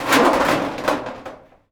metal_sheet_impacts_14.wav